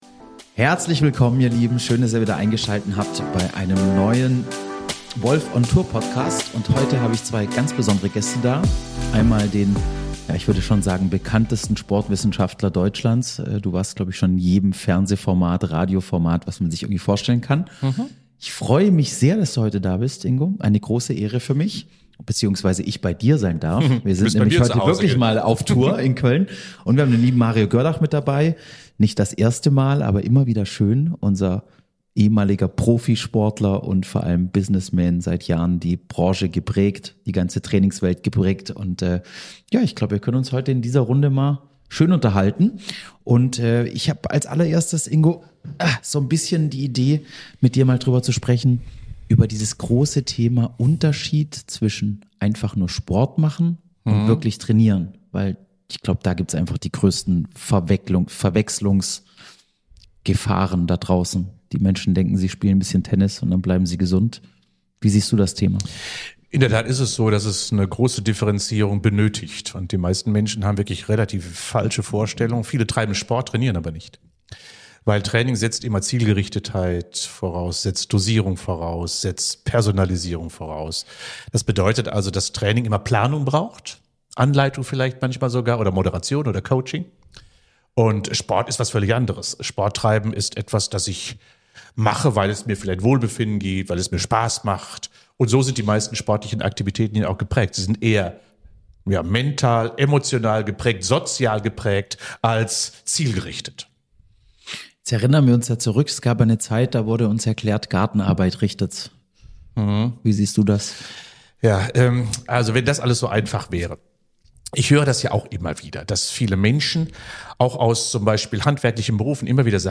Gemeinsam sprechen sie über den Unterschied zwischen Sport und Training, über Dosierung, Regeneration, Motivation - und warum gesund bleiben kein Zufall ist. Ein inspirierendes Gespräch für alle, die mehr aus ihrem Körper und Geist machen wollen.